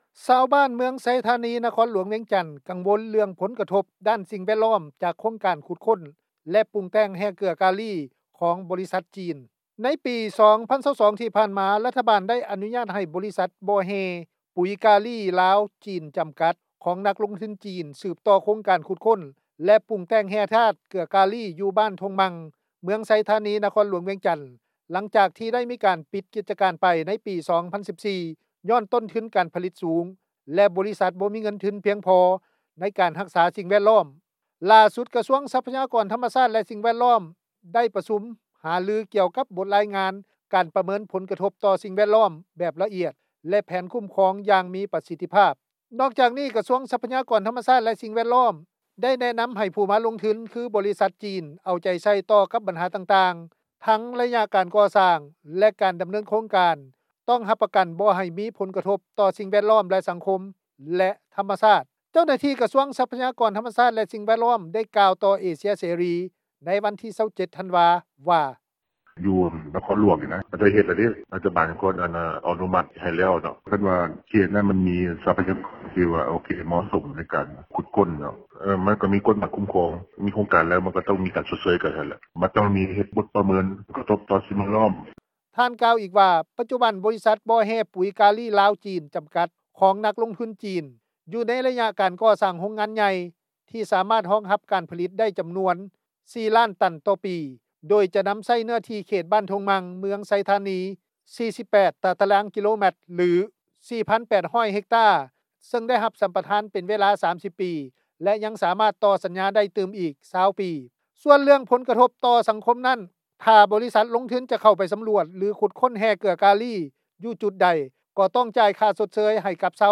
ເຈົ້າໜ້າທີ່ ກະຊວງຊັບພະຍາກອນ ທັມມະຊາດ ແລະ ສິ່ງແວດລ້ອມ ໄດ້ກ່າວຕໍ່ເອເຊັຽເສຣີ ໃນວັນທີ 27 ທັນວາ ວ່າ:
ເຈົ້າໜ້າທີ່ ກະຊວງອຸດສາຫະກັມ ແລະ ການຄ້າ ໄດ້ກ່າວຕໍ່ເອເຊັຽເສຣີ ໃນວັນທີ 27 ທັນວາ ວ່າ: